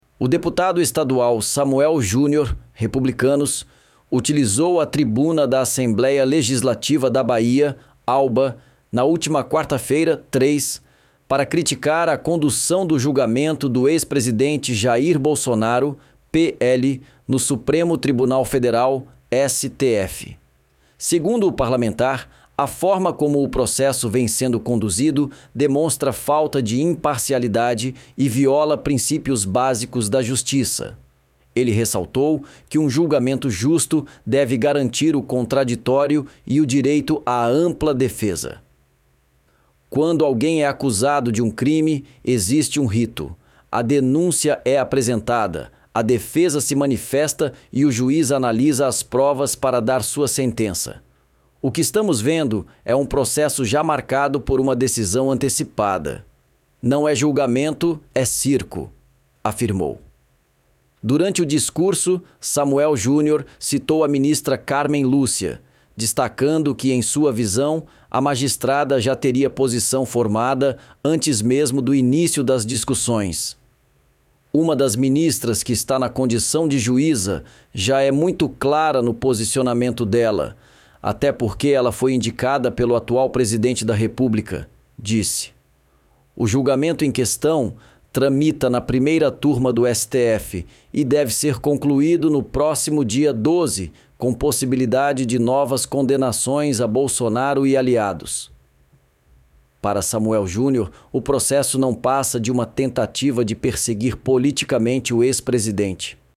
O deputado estadual Samuel Júnior (Republicanos) utilizou a tribuna da Assembleia Legislativa da Bahia (ALBA), na última quarta-feira (3), para criticar a condução do julgamento do ex-presidente Jair Bolsonaro (PL) no Supremo Tribunal Federal (STF).